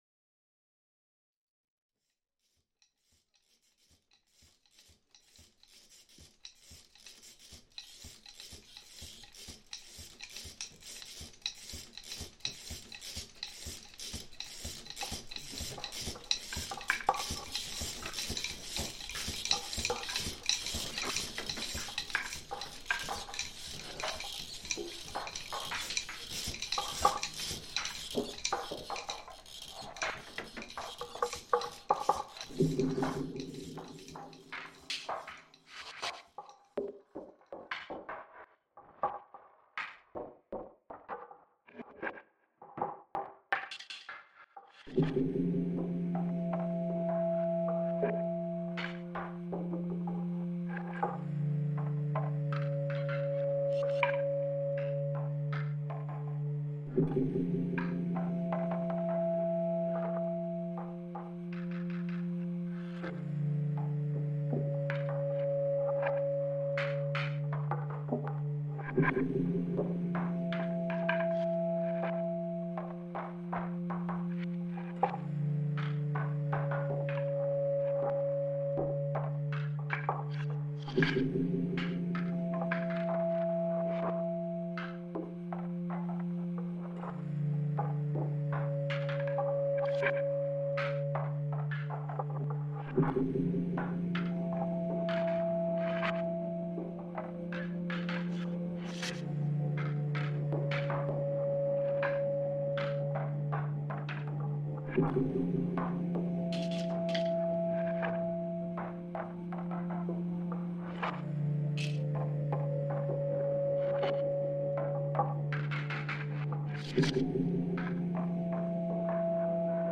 Traditional weaving in Ghana reimagined